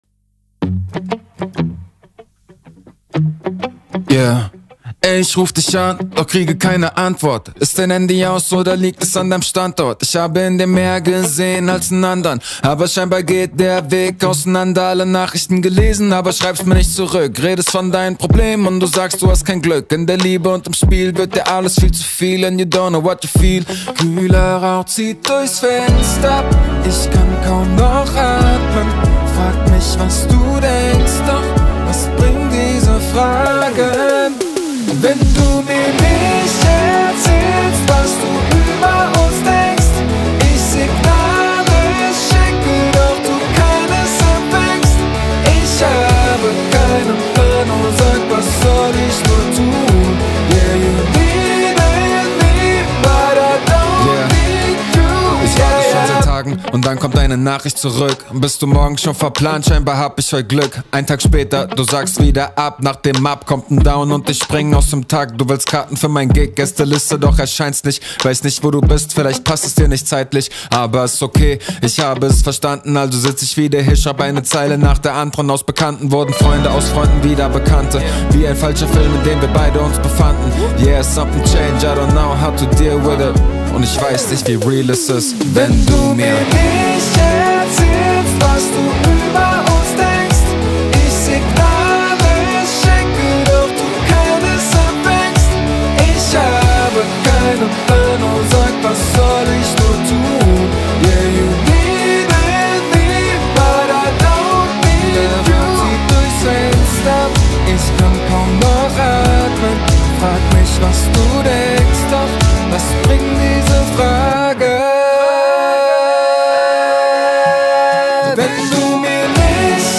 Specialized in Pop, Rock/Hard Rock, Indie, and Funk.